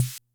Kick-Hat.wav